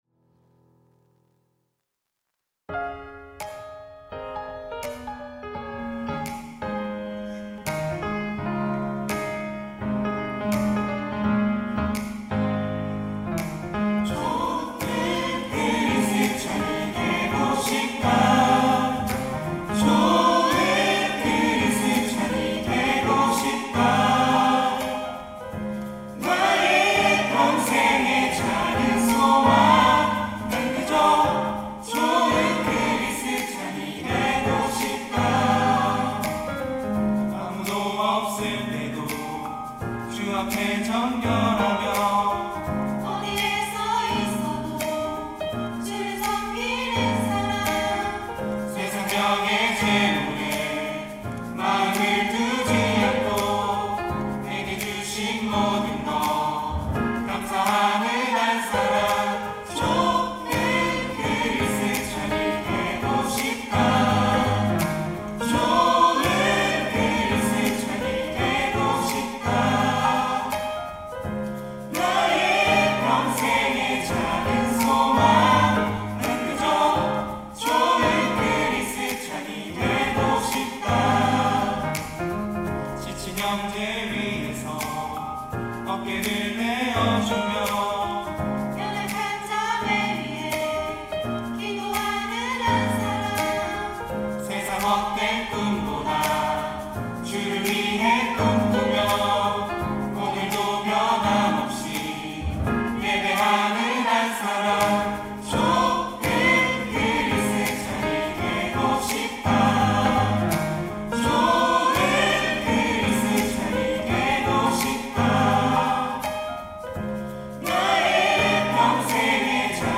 특송과 특주 - 좋은 크리스찬이 되고 싶다